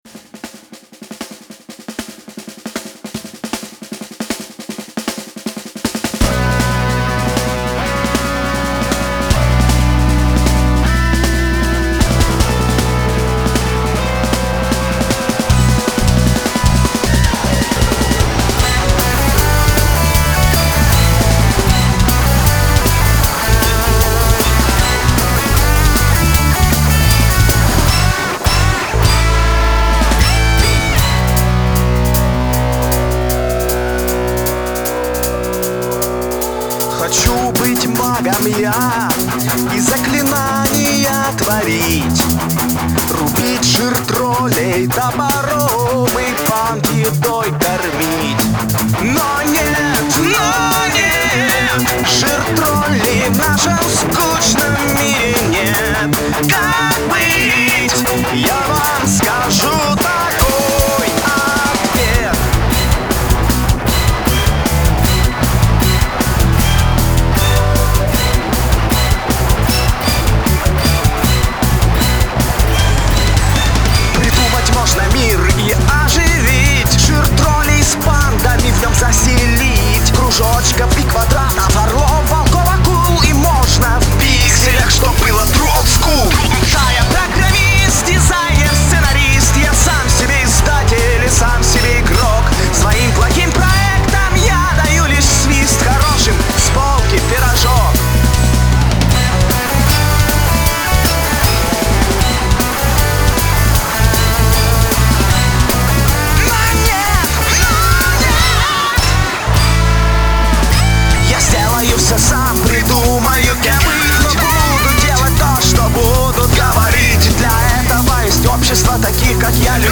Она в классическом стиле и чем-то даже напоминает AC/DC.
Металл